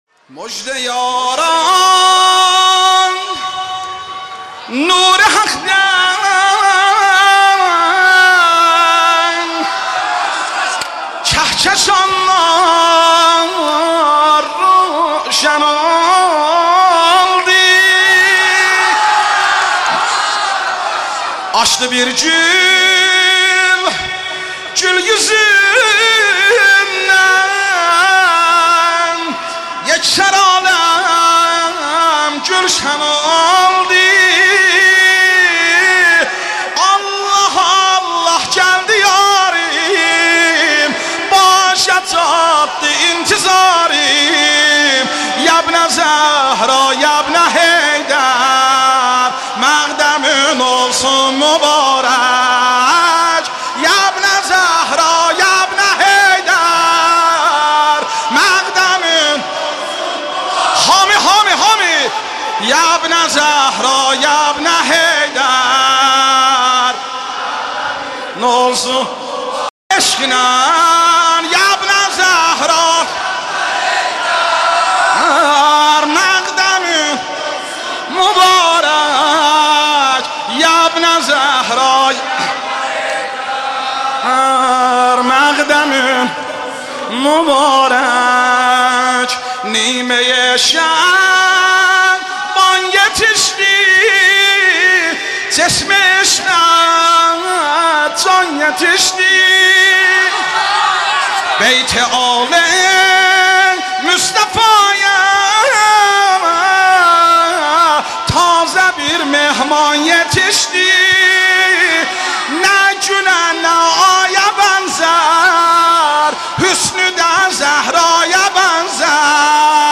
مداحی آذری
مولودی ترکی